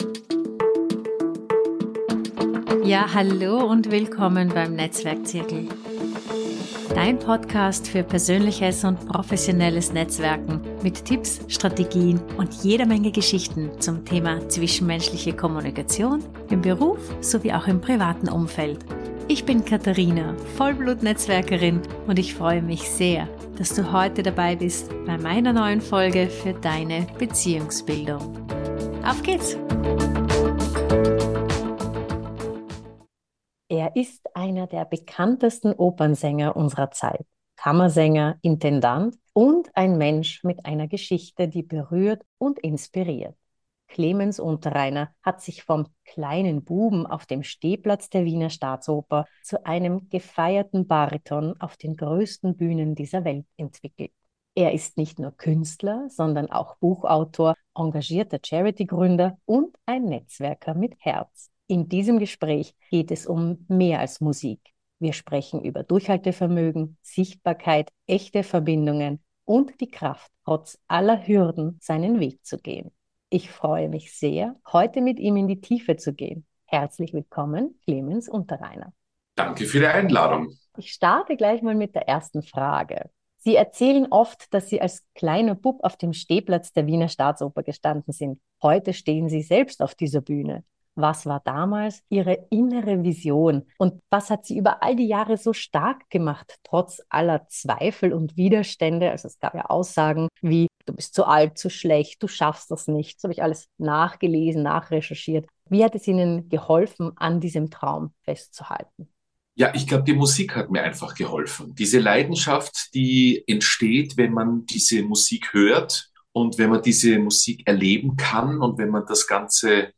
Clemens Unterreiner spricht über seinen Weg von der Erblindung in der Kindheit bis auf die größten Opernbühnen. Ein Gespräch über Kraft, Menschlichkeit und wie echte Netzwerke Leben verändern können – beruflich wie privat.